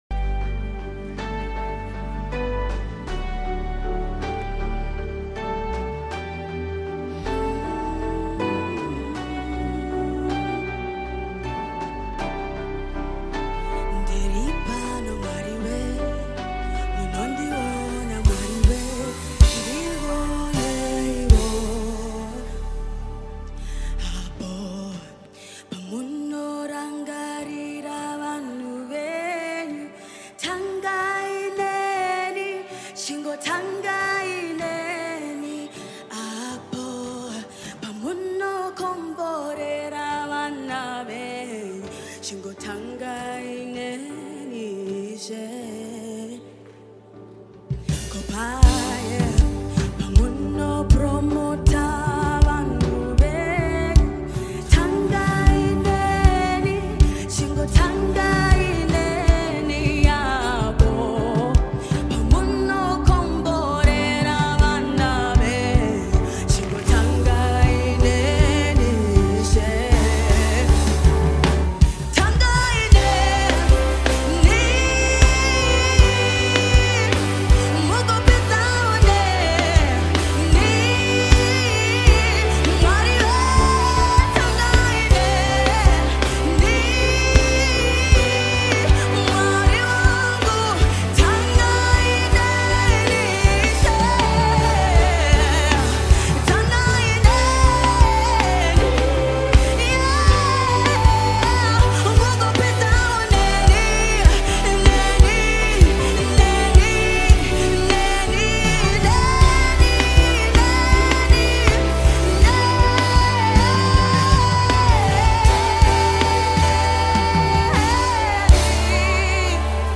Award winning singer-songsmith